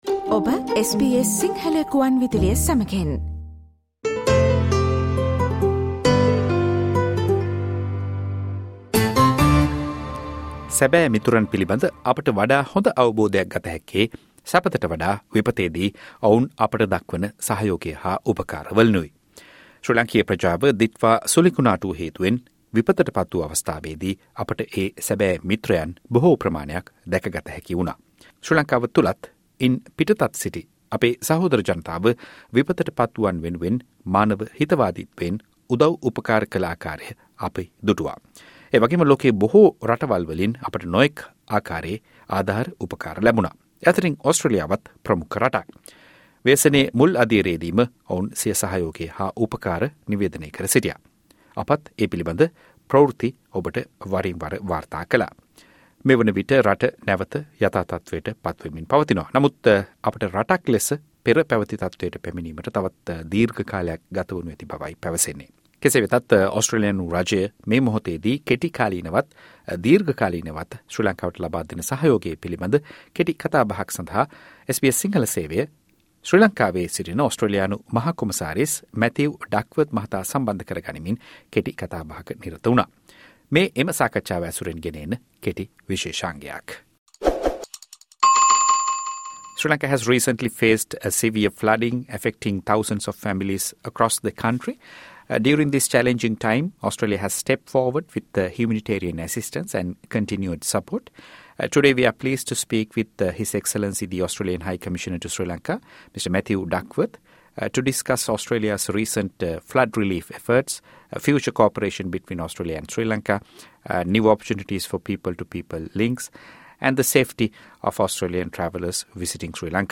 ශ්‍රී ලංකාවට යථා තත්ත්වයට ඒමට දීර්ඝ කාලයක් ගත වේවි - ශ්‍රී ලංකාවේ ඕස්ට්‍රේලියානු මහ කොමසාරිස් මැතිව් ඩක්වර්ත් SBS සිංහල සේවය සමඟ පැවති සාකච්ඡාව
ශ්‍රී ලාංකීය ප්‍රජාව දිට්වා සුළි කුණාටුව හේතුවෙන් විපතට පත් වූ අවස්ථාවේ දී විවිධ ආකාරයෙන් ආධාර උපකාර ලබා දීමට මුල් අවස්ථාවේ දී ම ඉදිරිපත් වූ රටවල් අතර ඕස්ට්‍රේලියානු රජය සහ ජනතාව ප්‍රමඛත්වයේ පසු වනවා. මේ ව්‍යසනකාරී අවස්ථාවේ දී ලබා දෙන සහයෝගය සහ දෙරට අතර පවතින දීර්ඝ කාලීන මිත්‍රත්වය පිළිබඳ SBS සිංහල සේවය ශ්‍රී ලංකාවේ ඕස්ට්‍රේලියානු මහ කොමසාරිස් මැතිව් ඩක්වර්ත් මහතා සමඟ පැවැත්වූ සාකච්ඡාව ඇසුරෙන් සැකසූ වැඩසටහනට සවන් දෙන්න.